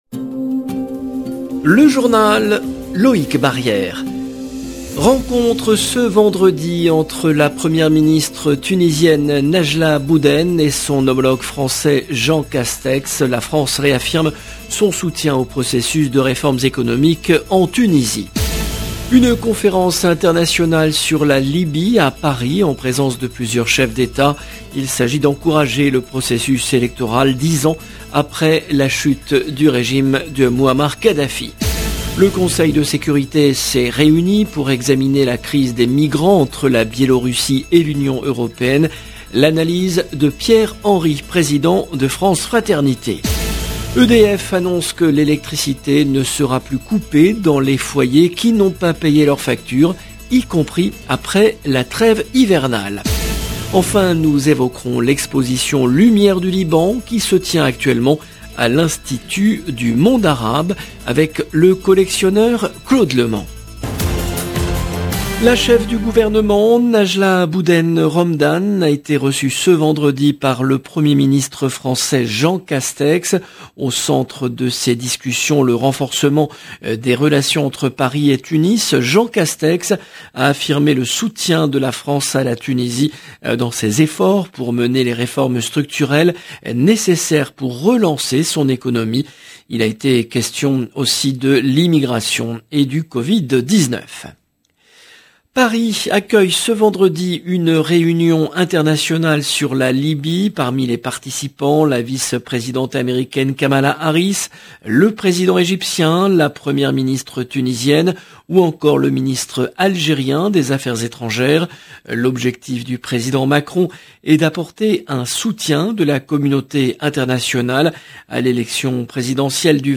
LE JOURNAL DU SOIR EN LANGUE FRANCAISE DU 12/11/21